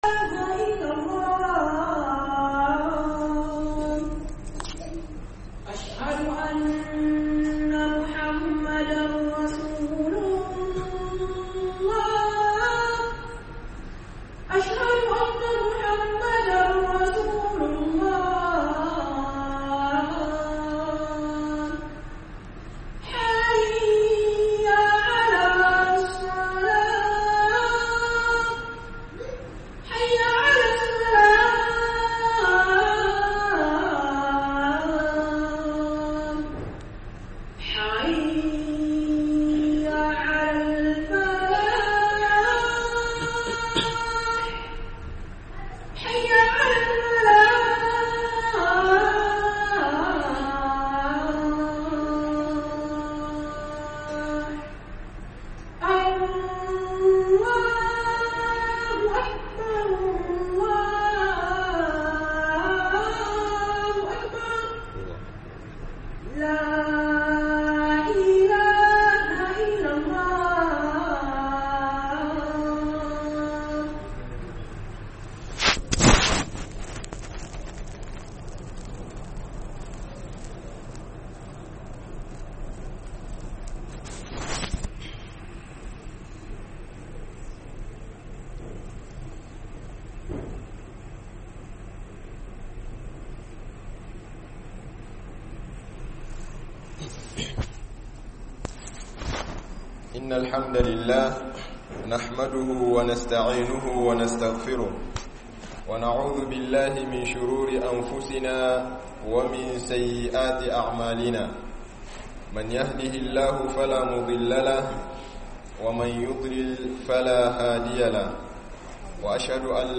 KURAKURAN MUSULMAI BAYAN WATAN RAMADAN - Hudubobi